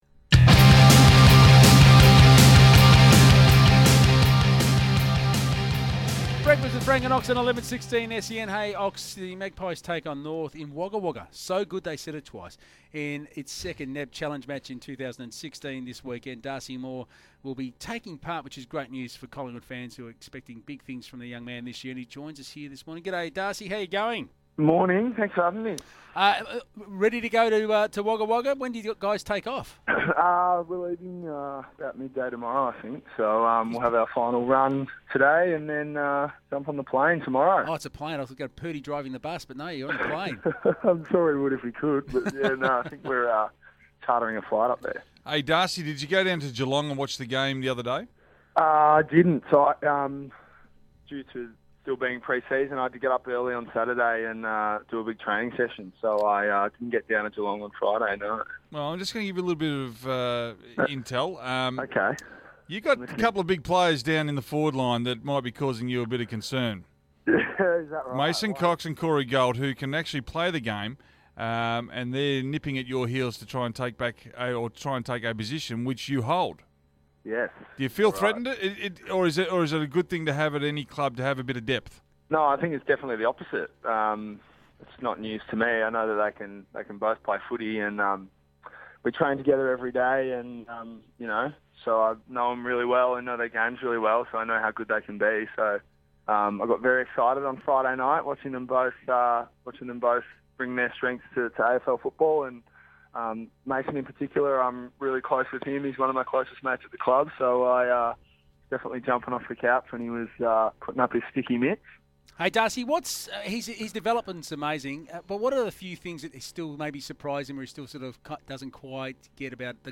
Radio: Darcy Moore on 1116 SEN